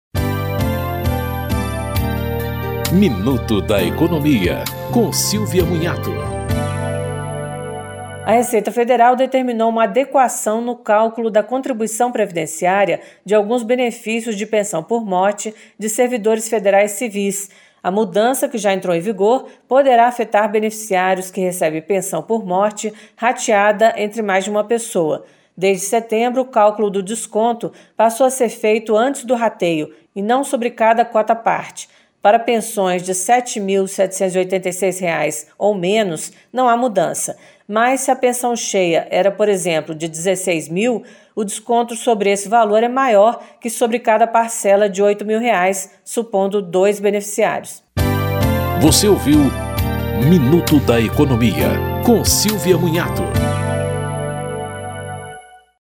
Programas da Rádio Câmara
Em um minuto, dicas sobre direitos do consumidor, pagamento de impostos e investimentos.